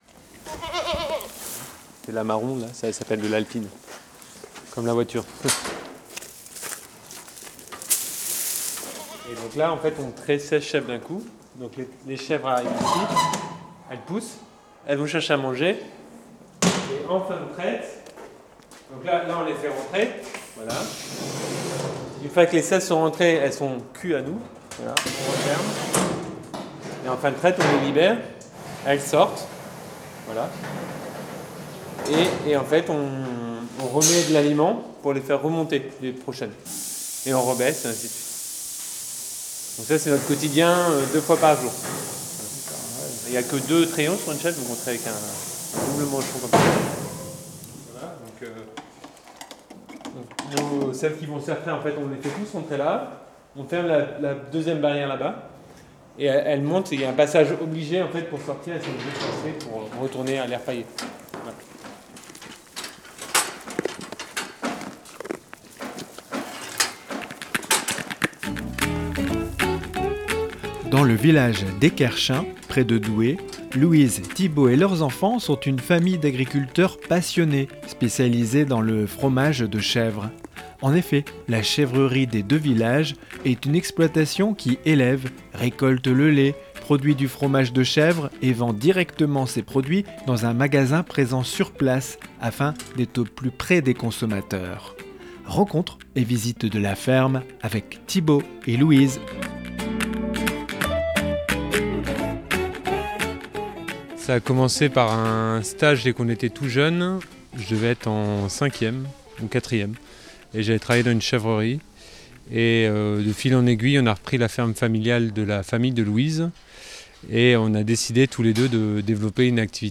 Rencontre et visite de la ferme
REPORTAGE-2504-La-Chevrerie-des-2-villages-a-Esquerchin.mp3